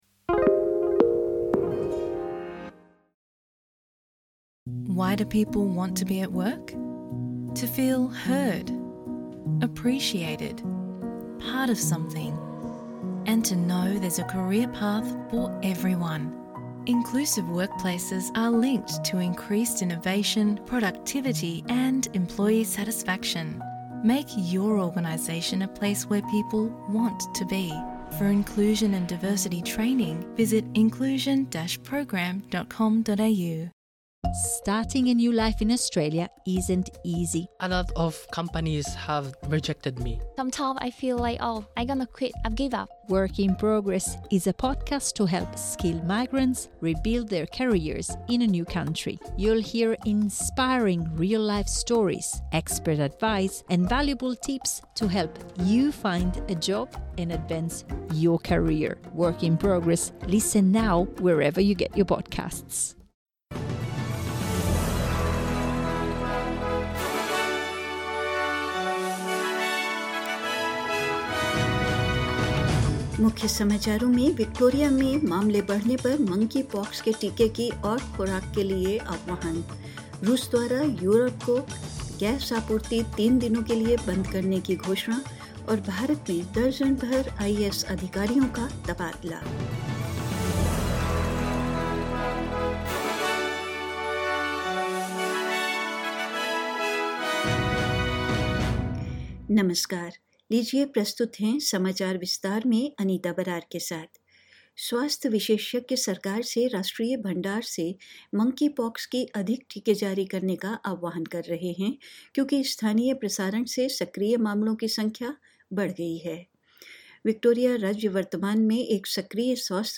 In this latest SBS Hindi bulletin: Health experts call for further doses of Monkeypox vaccine as cases grow in Victoria; Russia announces a three-day shutdown of its gas supply to Europe; Immediate transfer of a dozen Indian Administrative Services (IAS) officers in India and more news.